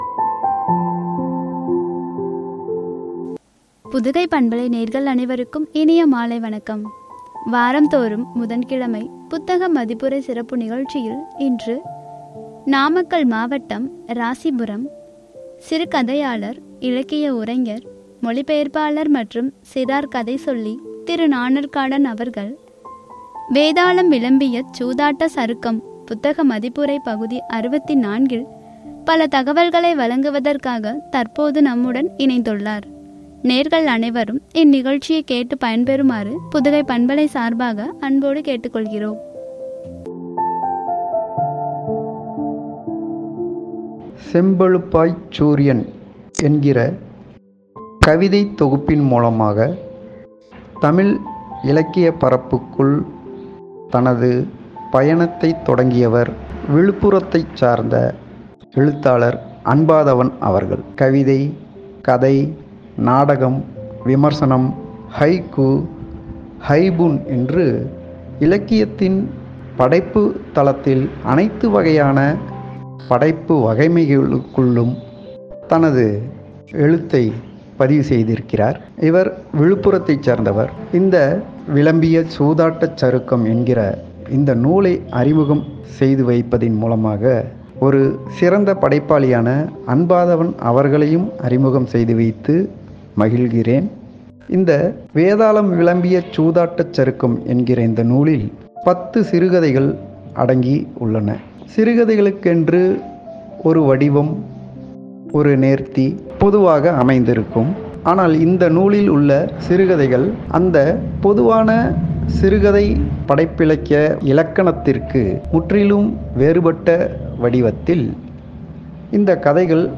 (புத்தக மதிப்புரை – பகுதி – 64) குறித்து வழங்கிய உரை.